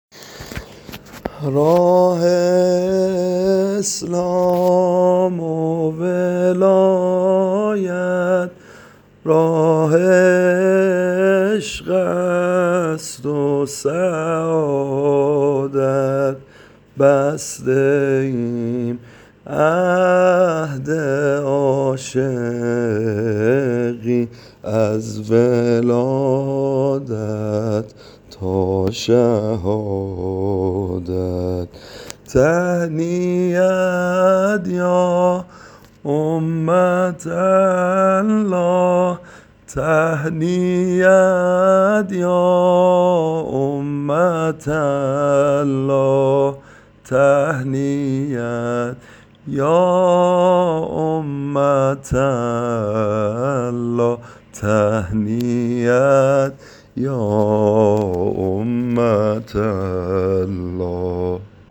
عنوان : به این سبک خوانده میشود